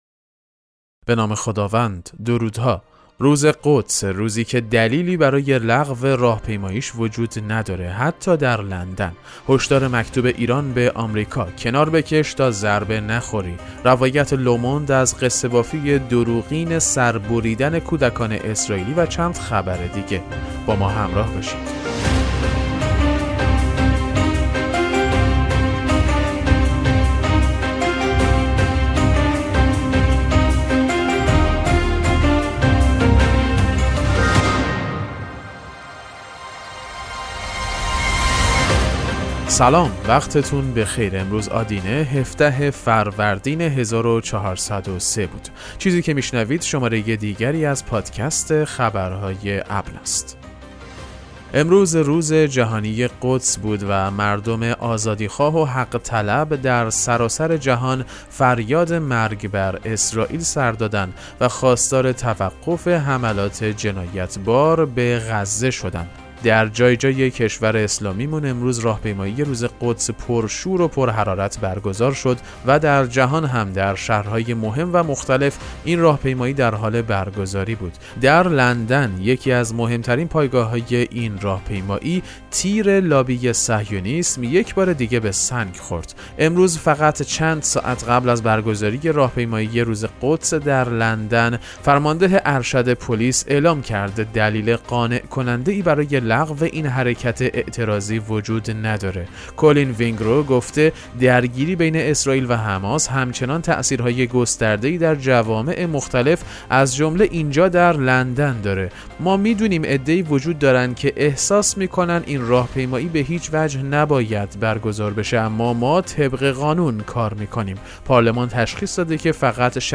پادکست مهم‌ترین اخبار ابنا فارسی ــ 17 فروردین 1403